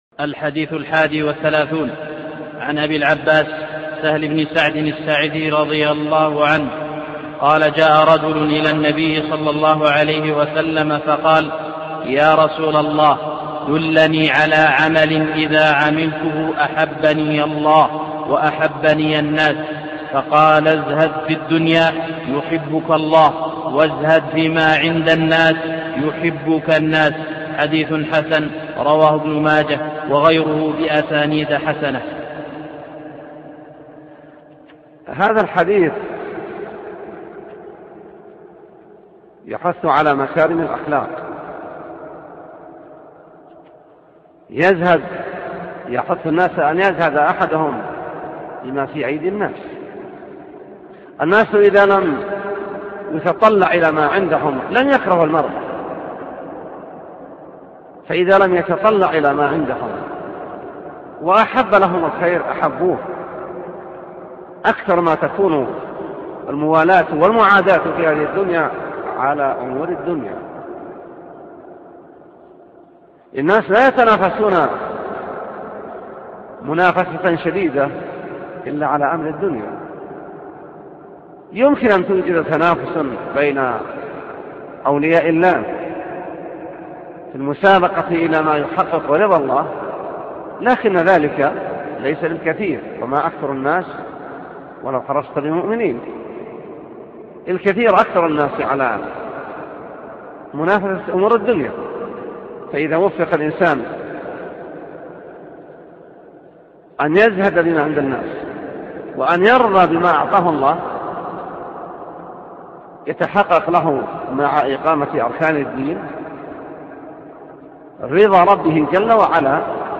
شرح الشيخ العلامة الدكتور صالح اللحيدان